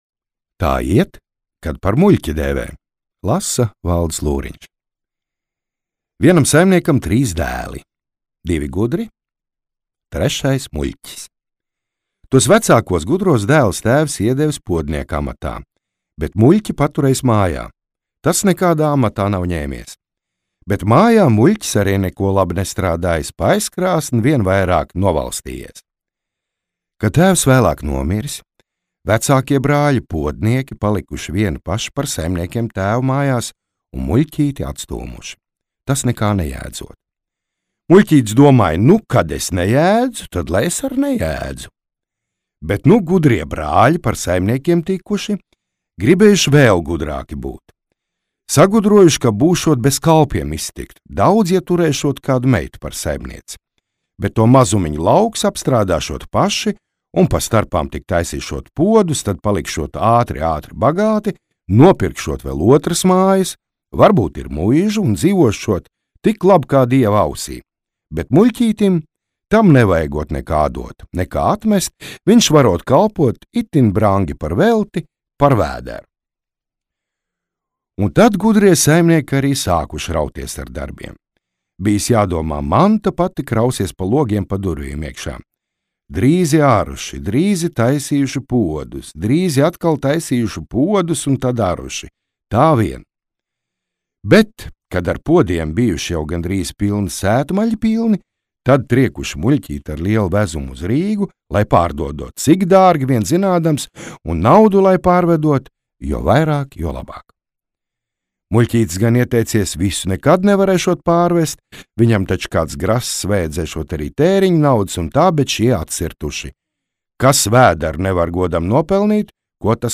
Teicējs